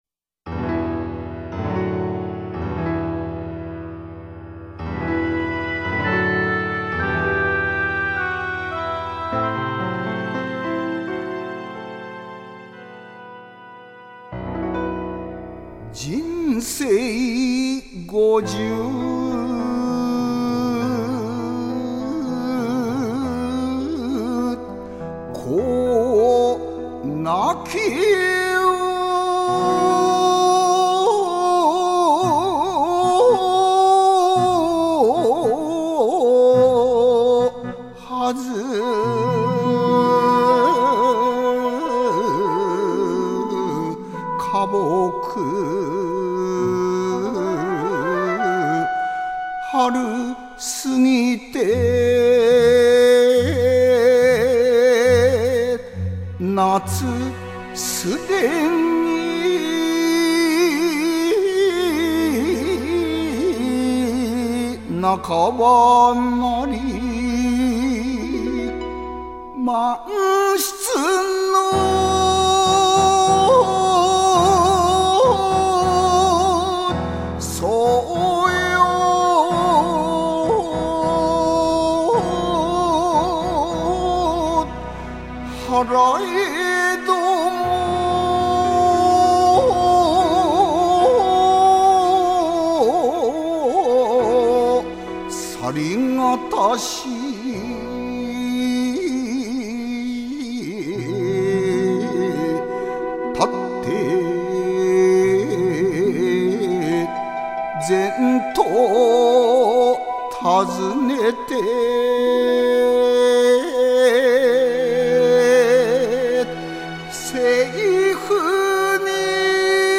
吟者